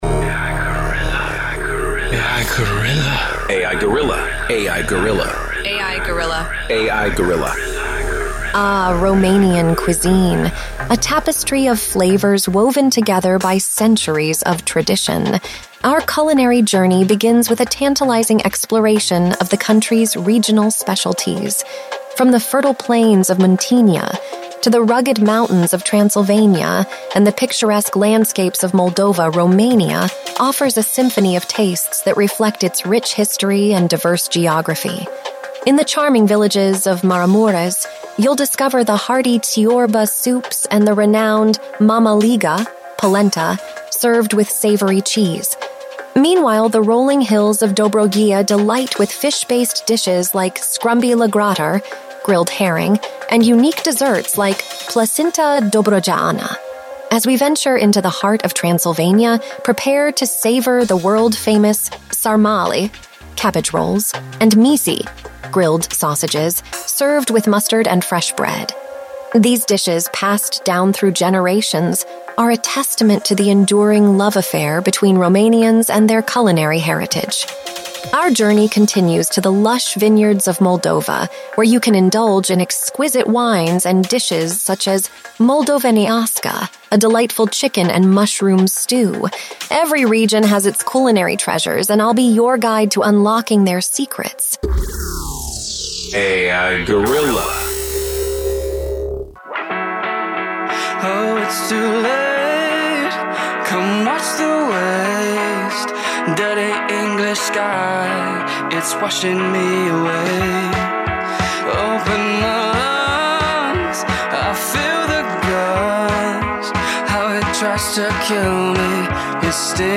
Pe 6 martie 2023, de la ora 10 dimineața, am difuzat “A.I. Guerrilla”, prima emisiune radio realizată de Inteligența Artificială în FM-ul românesc din câte știm noi, dacă nu chiar în Galaxie pe România, un experiment care va dura o oră.